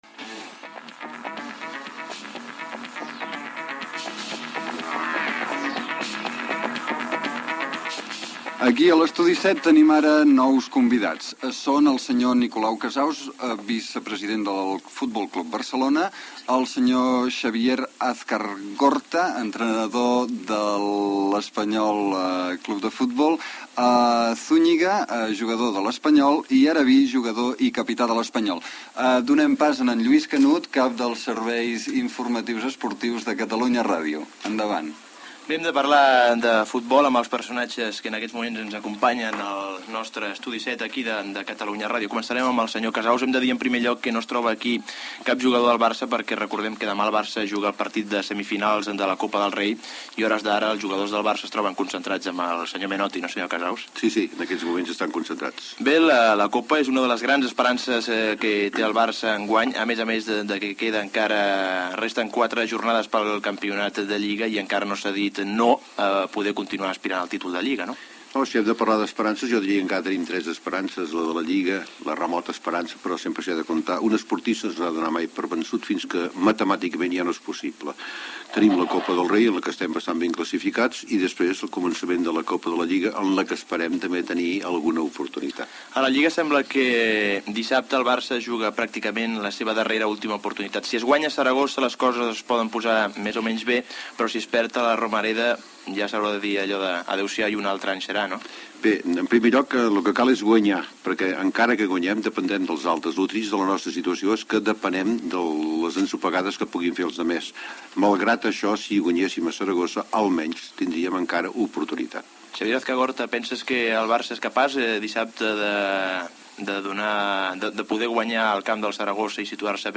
Promoció de la inauguració de la nova seu de l'emissora. Gènere radiofònic Esportiu